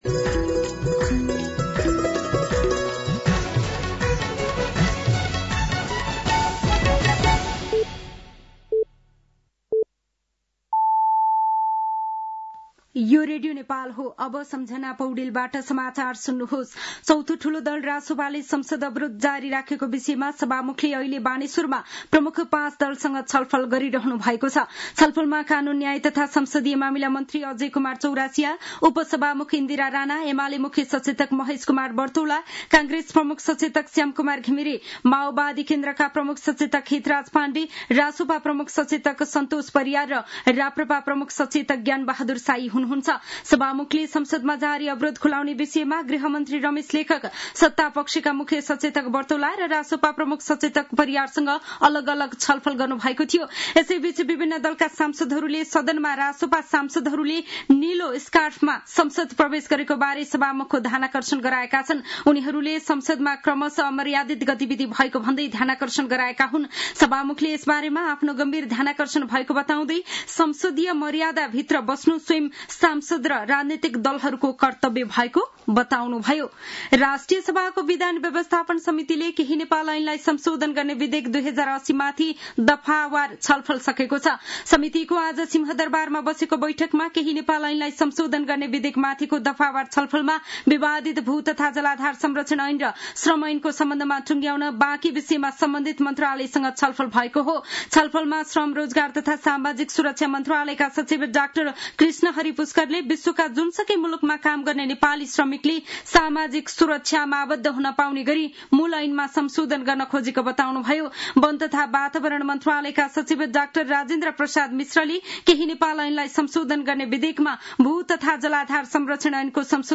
साँझ ५ बजेको नेपाली समाचार : ३ असार , २०८२